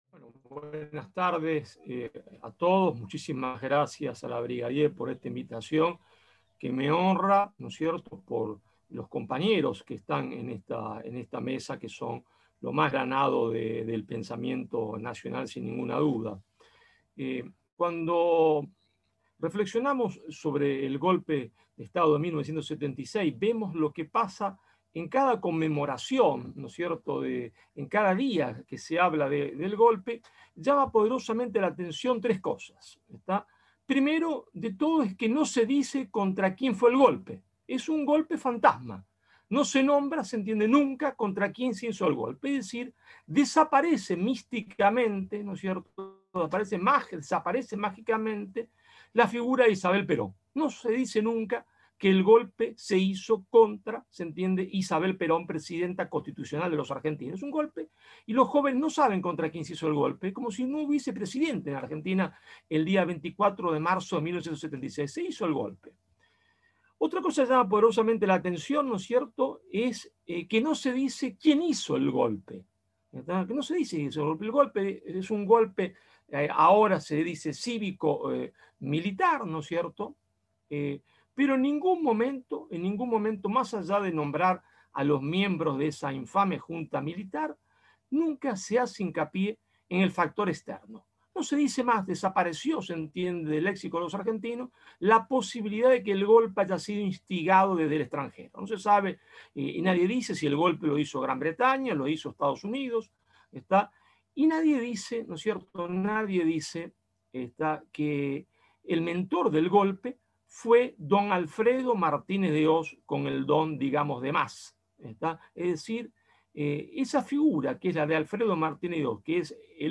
Intervención en vivo